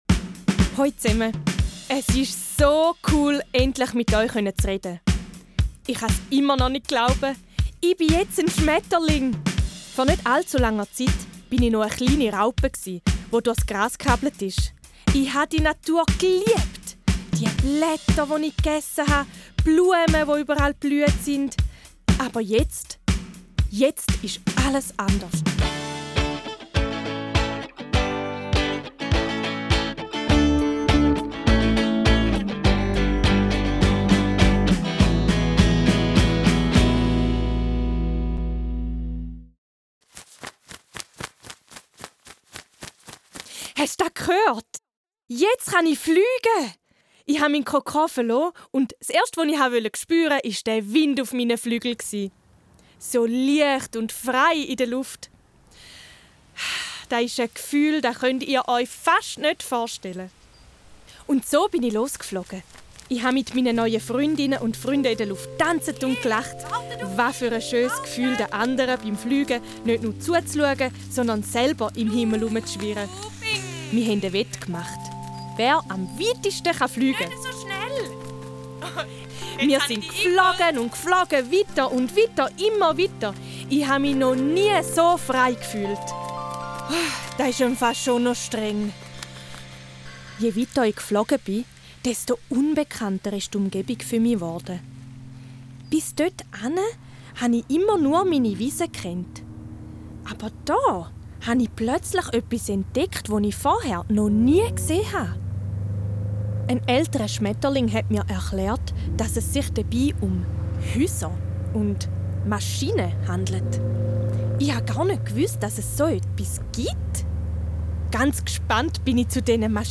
LS Posten Luft Am Posten Luft erzählt Schmetterling Orion euch, was er an der der Insektenkonferenz ansprechen möchte. Startet mit dem Hörspiel: Hört euch das Hörspiel an, um mehr über die Bedeutung der Luft und die Probleme der Luftverschmutzung zu erfahren.